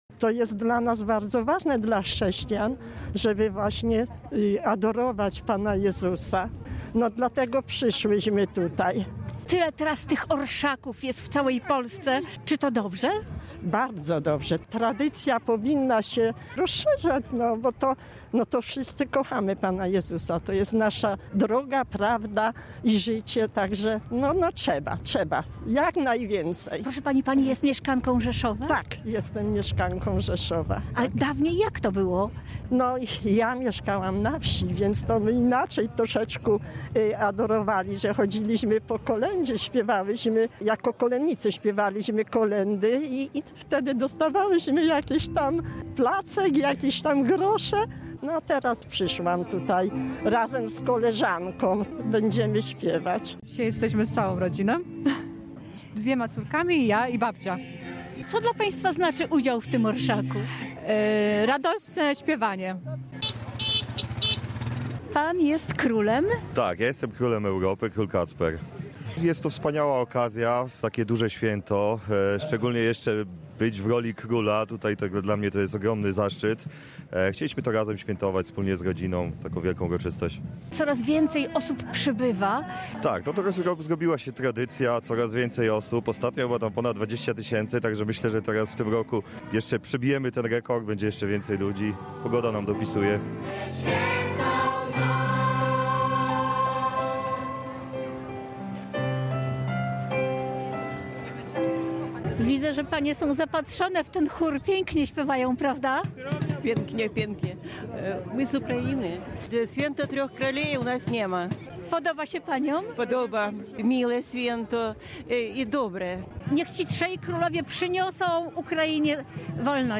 Uroczystości zakończyły się wspólnym kolędowaniem na rzeszowskim Rynku.